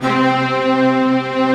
Index of /90_sSampleCDs/Optical Media International - Sonic Images Library/SI1_Fast Strings/SI1_Fast octave